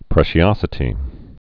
(prĕshē-ŏsĭ-tē, prĕs-)